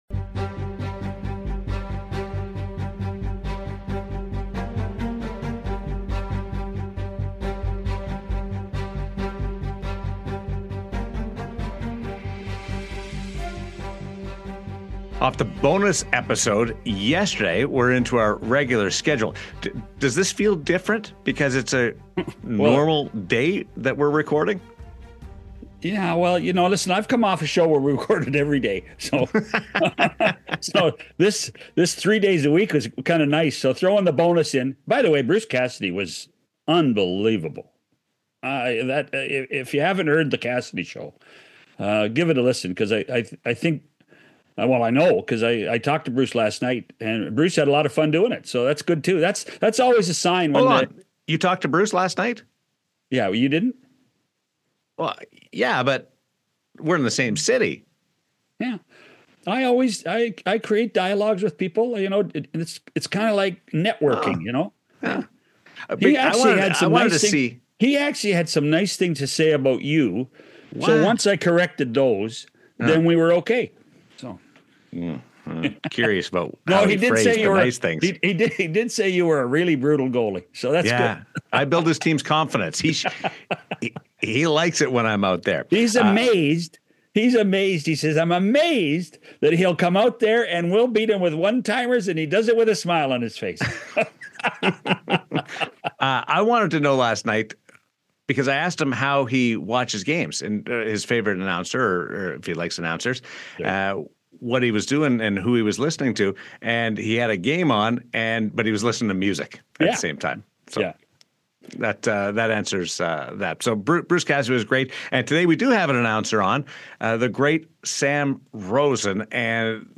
In this heartfelt episode of 100% Hockey, John Shannon and Daren Millard sit down with broadcasting legend Sam Rosen as he prepares to retire after a remarkable career with the New York Rangers.